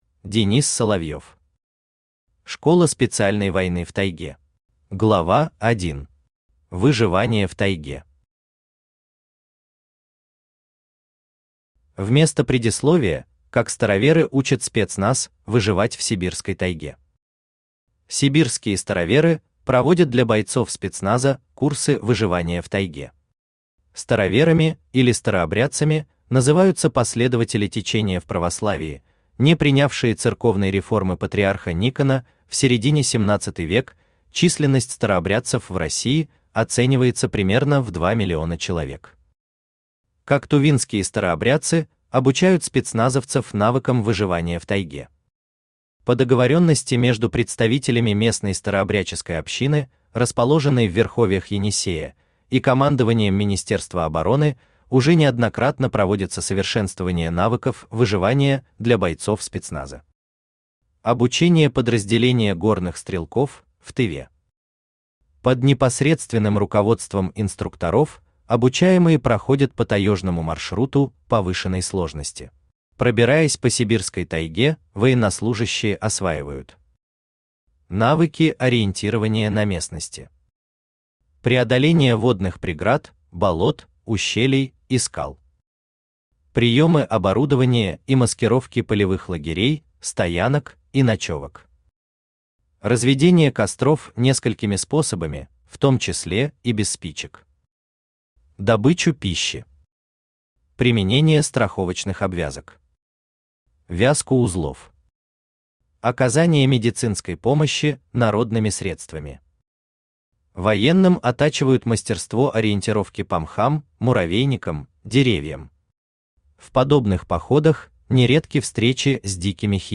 Аудиокнига Школа специальной войны в Тайге | Библиотека аудиокниг
Aудиокнига Школа специальной войны в Тайге Автор Денис Соловьев Читает аудиокнигу Авточтец ЛитРес.